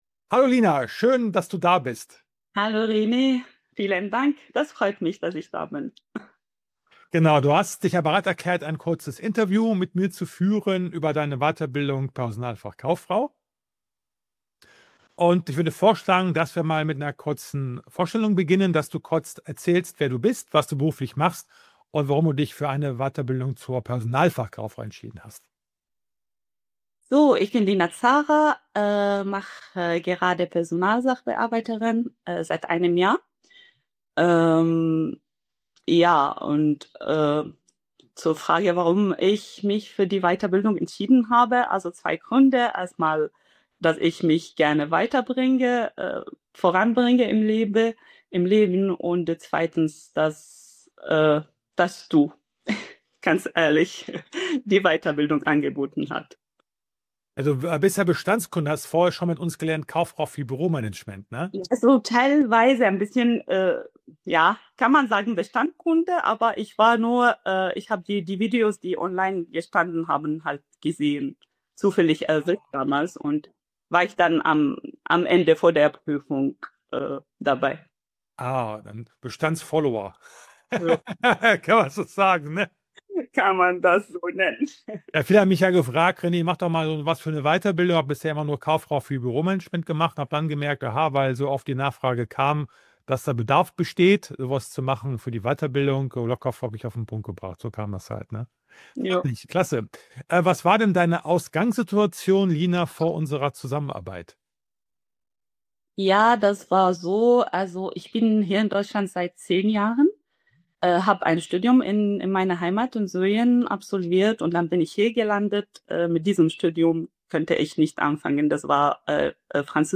In diesem ehrlichen Interview